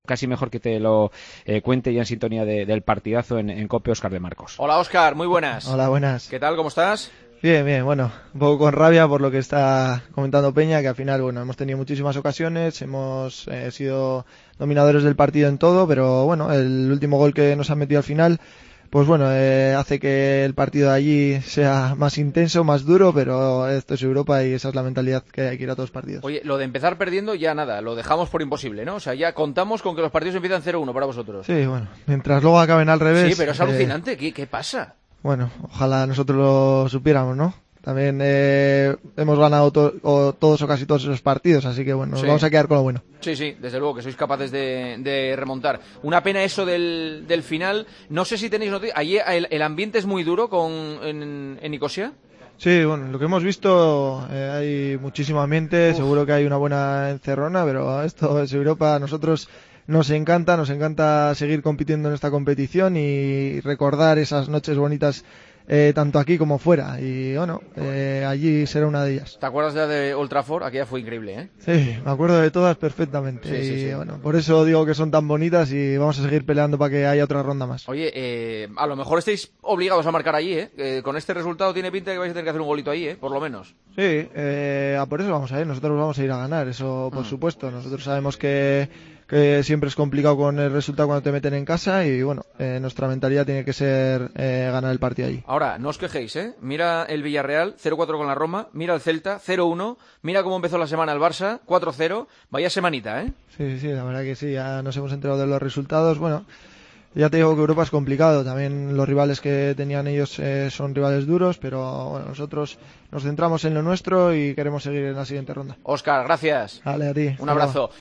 Óscar de Marcos habló con Juanma Castaño tras ganar por la mínima al APOEL: "Estamos con un poco de rabia porque hemos sido dominadores y el gol que nos meten al final hará que el partido en la vuelta sea más duro. Ojalá supiéramos por qué empezamos encajando, lo bueno es cómo terminan. En Nicosia seguro que hay una buena encerrona, pero esto es Europa y nos gusta esta competición".